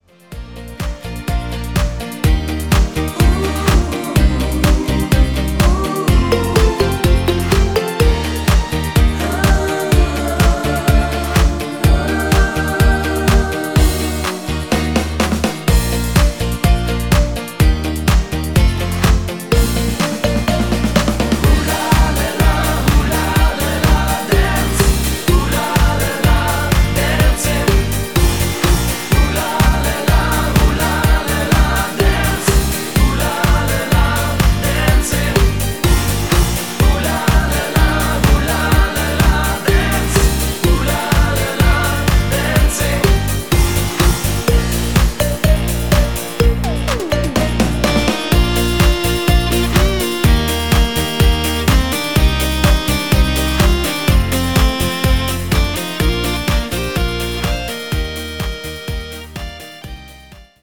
new Mix